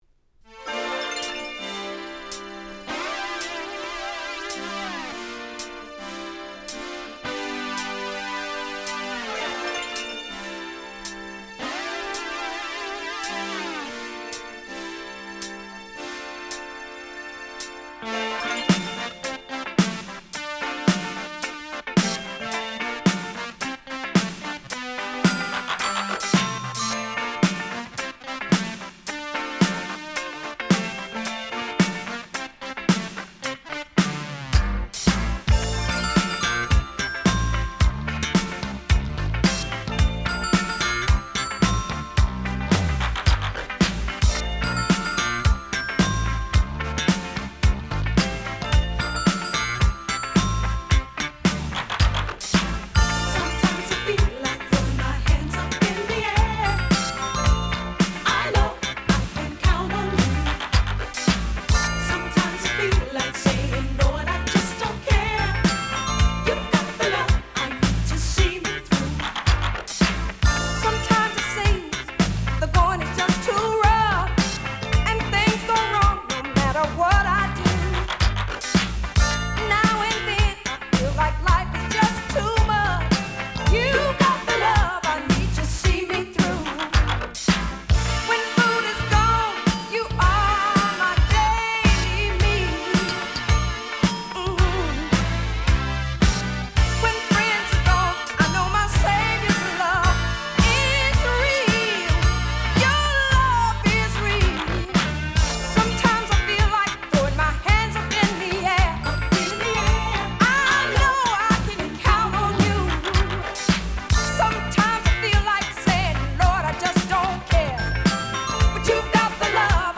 Genre: Disco.